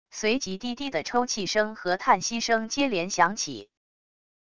随即低低的抽泣声和叹息声接连响起wav音频